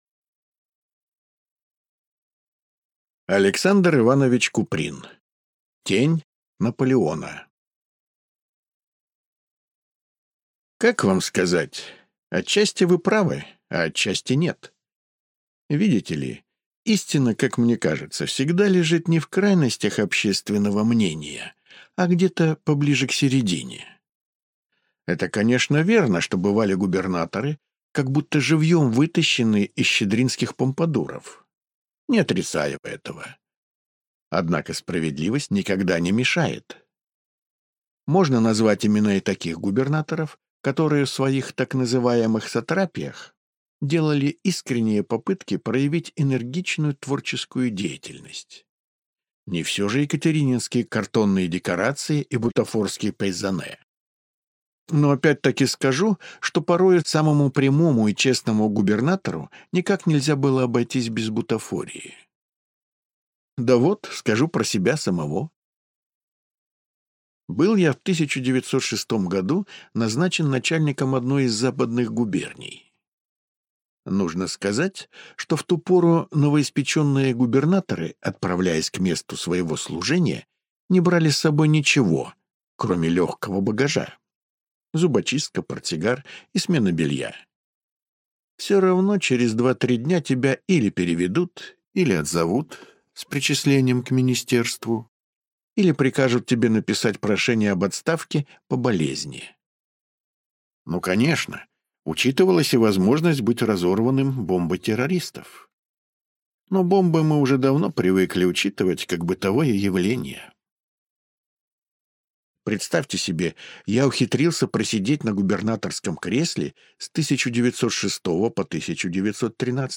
Аудиокнига Тень Наполеона